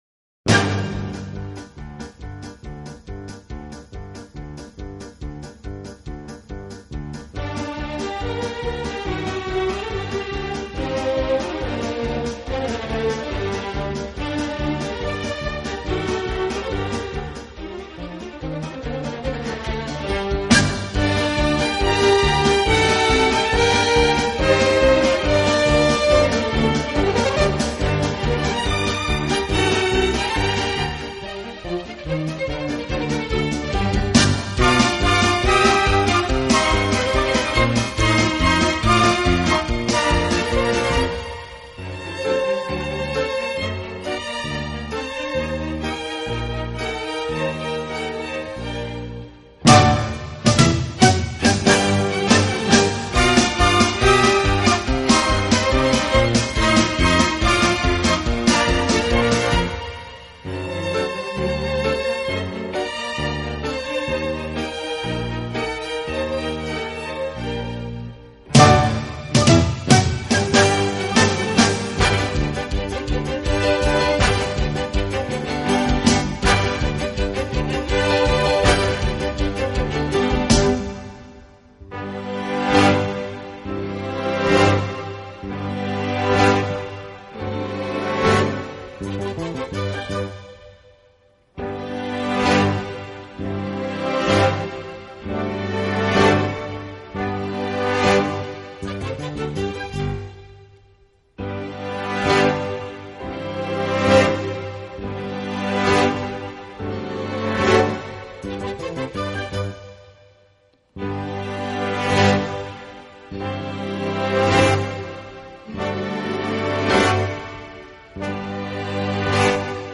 【轻音乐】
“清新华丽，浪漫迷人”